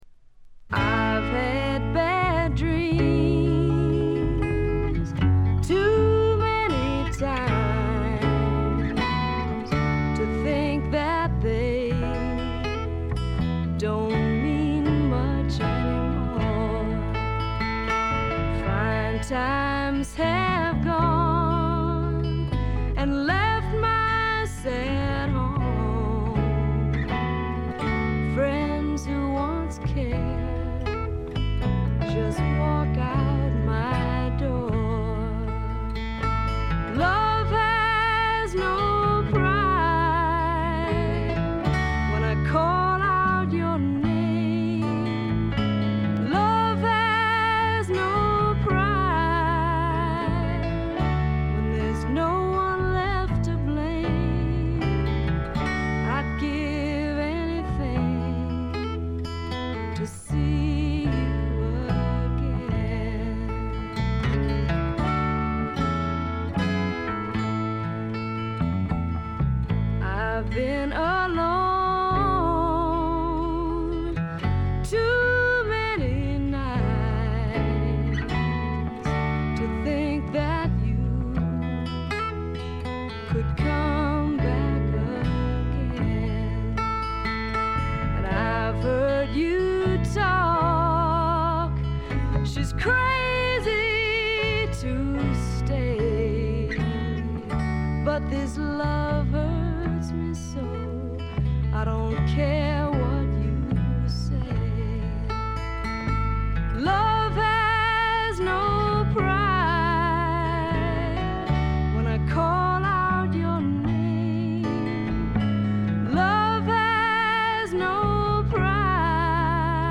ウッドストック・べアズビル録音の名盤としても有名です。